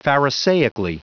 Prononciation du mot pharisaically en anglais (fichier audio)
Prononciation du mot : pharisaically
pharisaically.wav